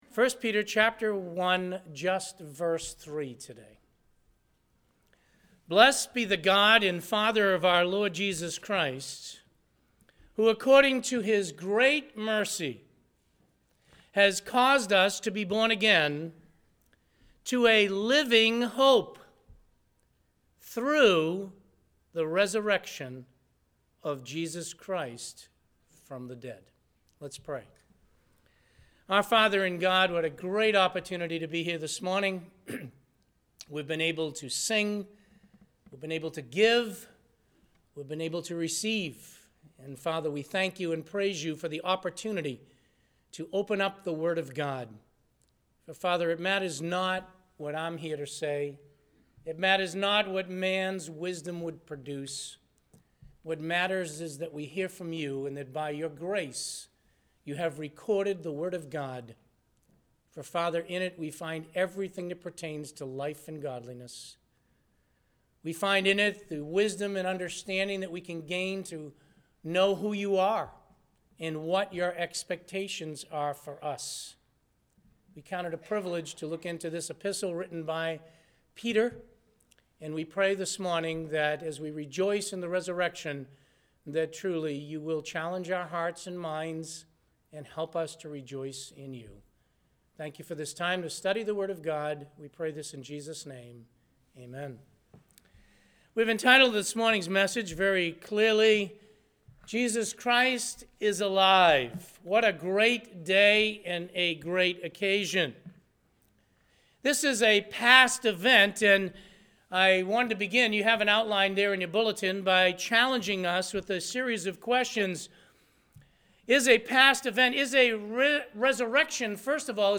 Listen to the sermon “Jesus Christ is Alive!.”